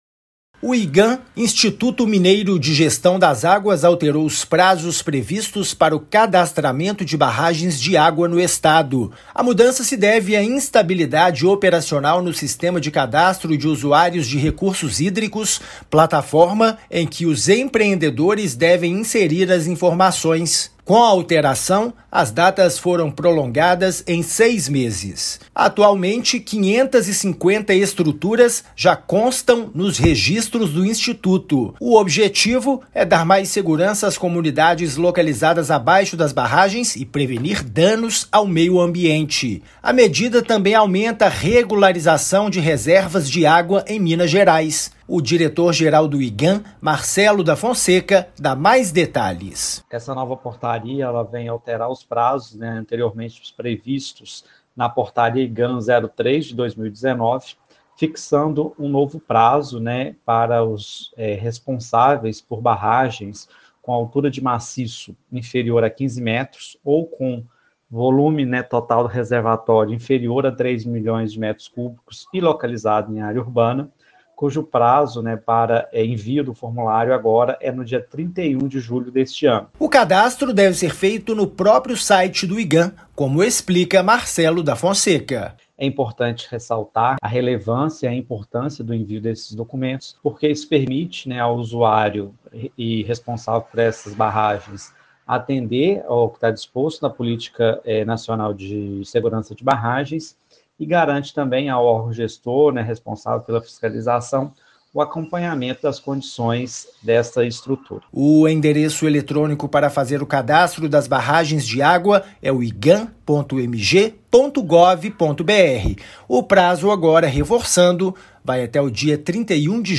MATÉRIA_RÁDIO_IGAM.mp3